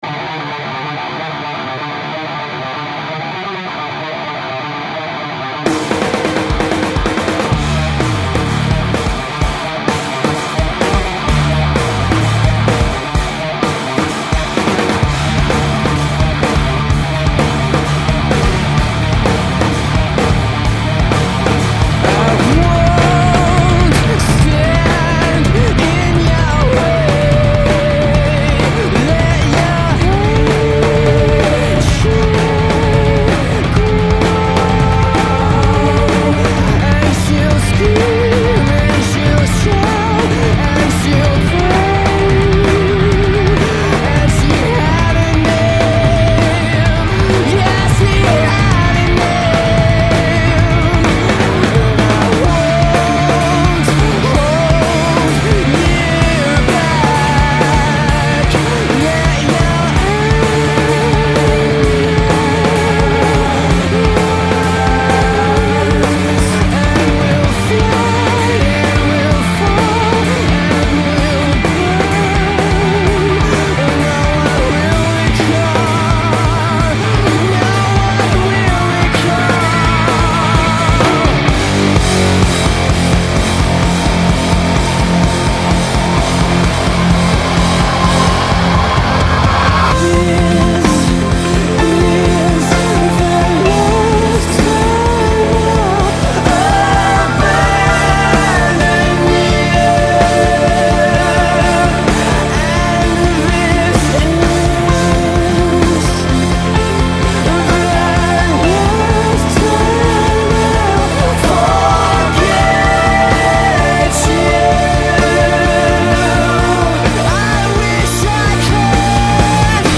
Рок Альтернативный рок Alternative